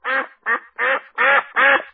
Звуки и голос уток
Грубый, резкий звук крякания